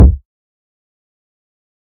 damage_medium.ogg